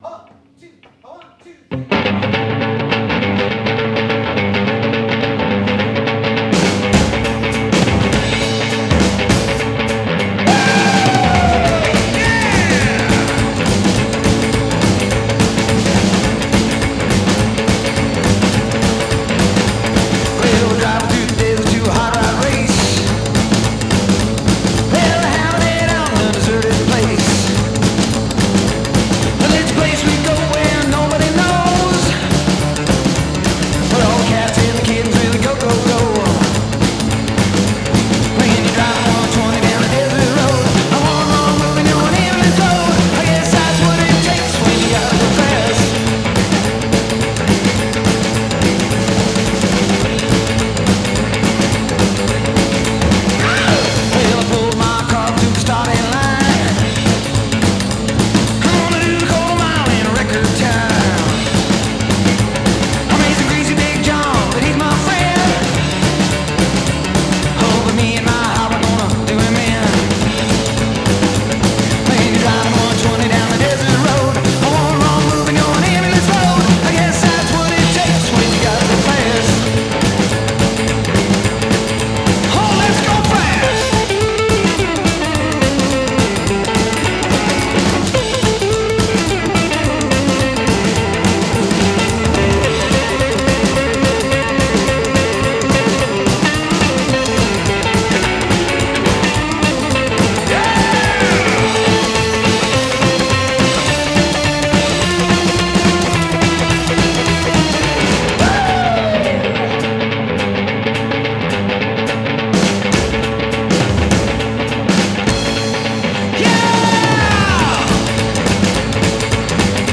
The Rockabilly Rhythms pound out on this killer album